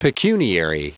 Prononciation du mot pecuniary en anglais (fichier audio)
Prononciation du mot : pecuniary